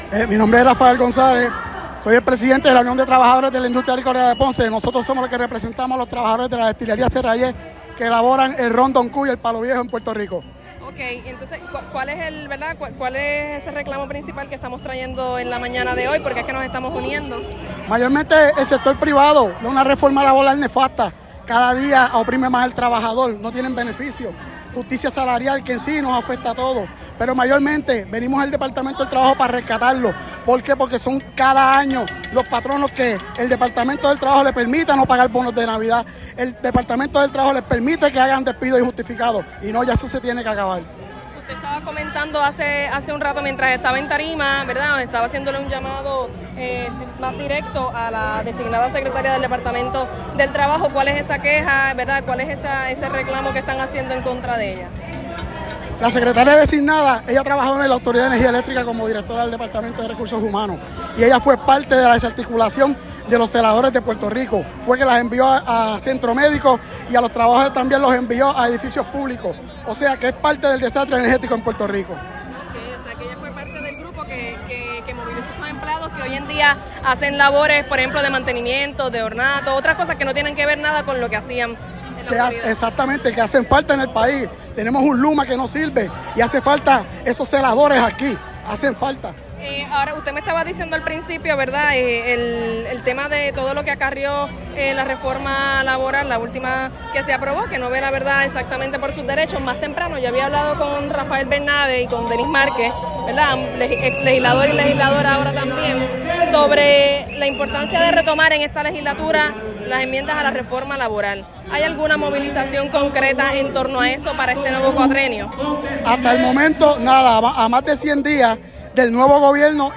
No tienen beneficios además de un salario malo”, indicó en entrevista para este medio.